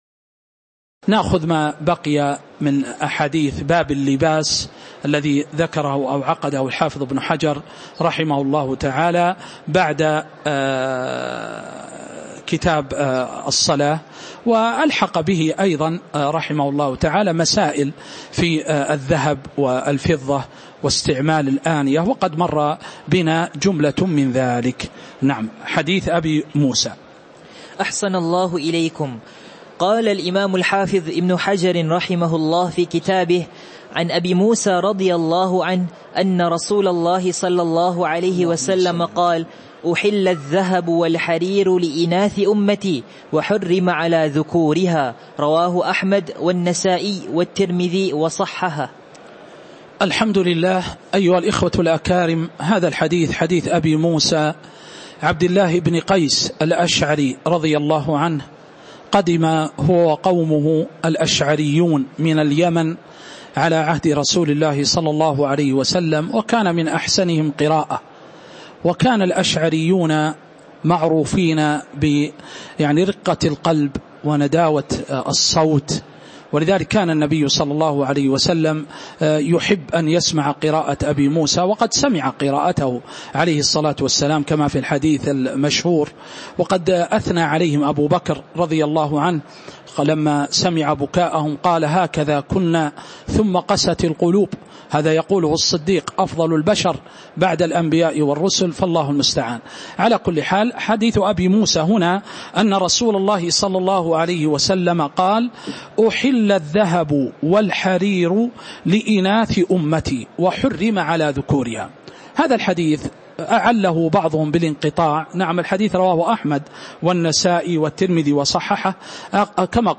تاريخ النشر ٩ شعبان ١٤٤٥ هـ المكان: المسجد النبوي الشيخ